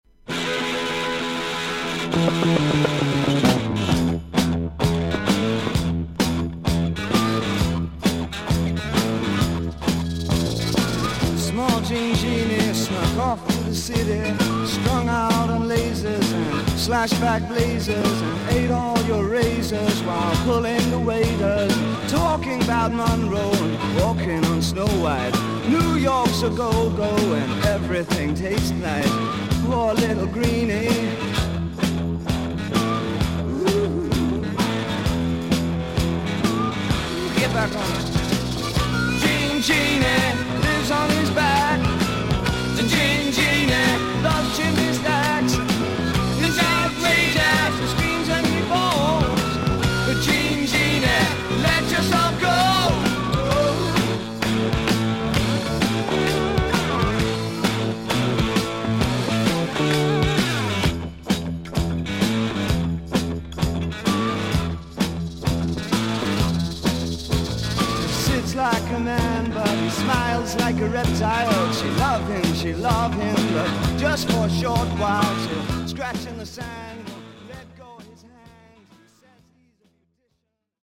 盤面に浅いスジが見えますが音に出ません。音のグレードはVG++:少々軽いパチノイズの箇所あり。クリアな音です。